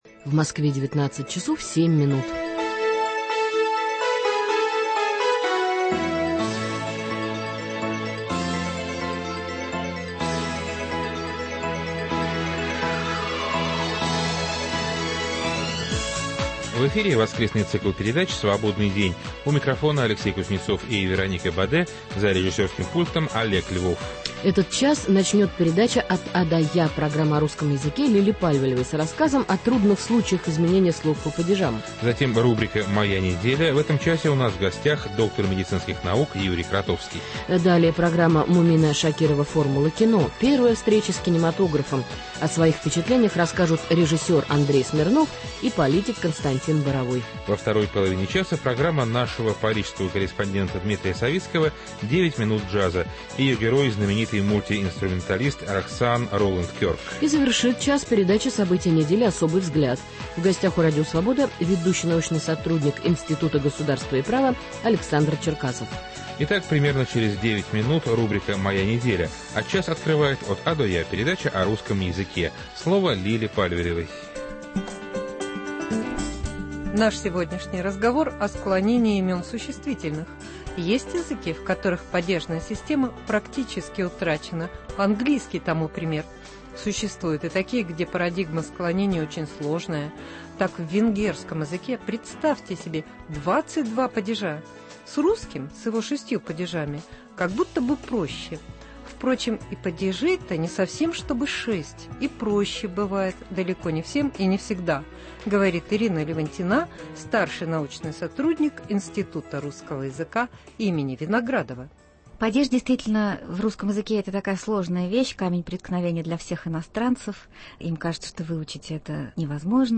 После выпуска новостей - передача "От А до Я" с рассказом о трудных случаях изменения слов по падежам.
О своих впечатлениях расскажут режиссер Андрей Смирнов и политик Константин Боровой. Во второй половине часа – программа «9 минут джаза»: ее герой - мульти-инструменталист Рахсаан Ролэнд Кёрк.